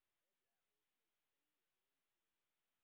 sp08_white_snr0.wav